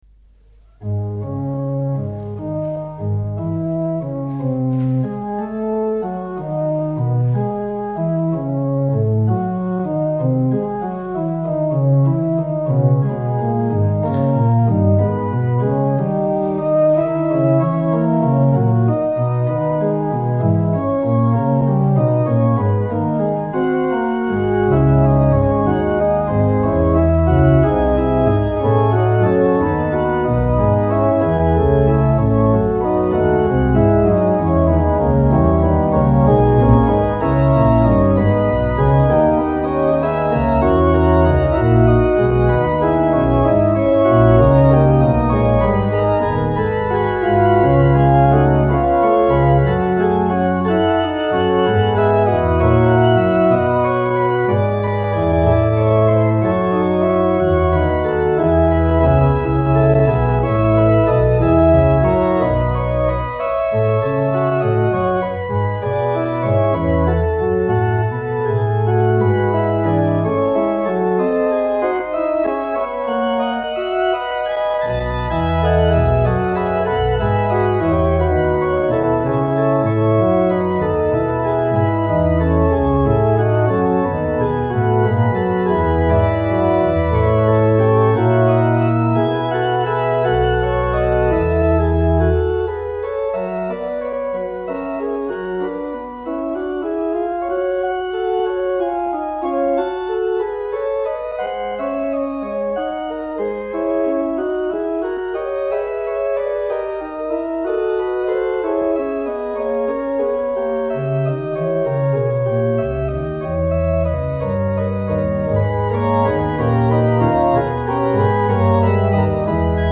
Eglise Notre Dame de la Paix MACON
Extraits du concert